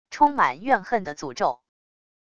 充满怨恨的诅咒wav音频